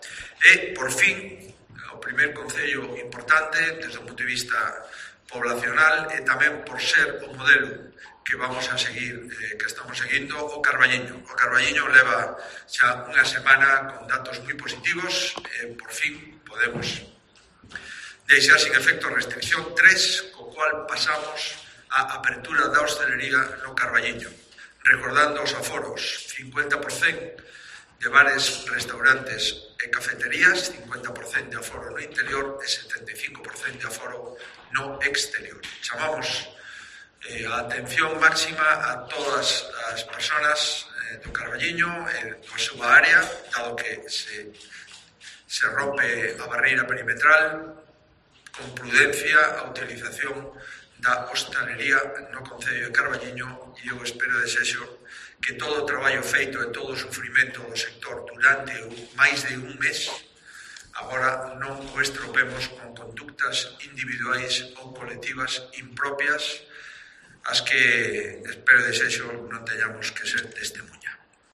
Declaraciones del presidente de la Xunta, Alberto Núñez Feijóo, sobre O Carballiño